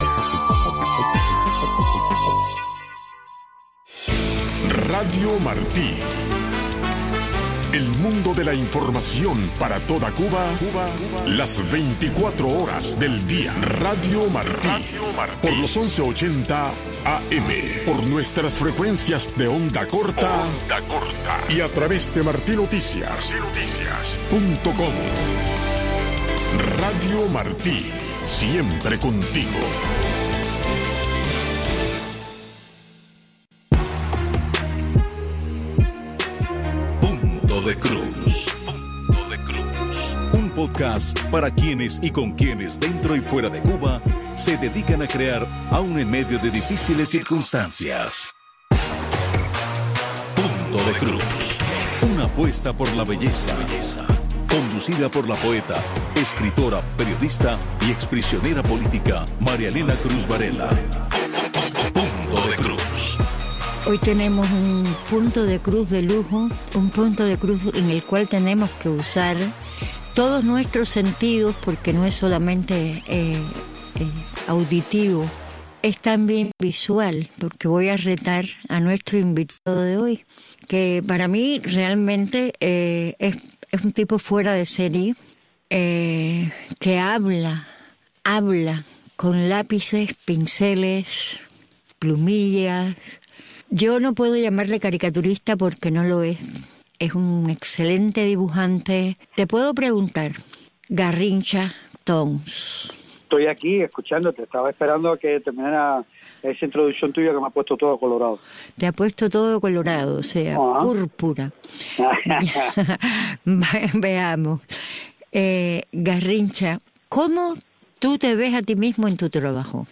En este espacio informativo de Radio Martí escuche de primera mano los temas que impactan el día a día de los cubanos dentro de la Isla. Voces del pueblo y reportes especiales convergen para ofrecerle una mirada clara, directa y actual sobre la realidad cubana.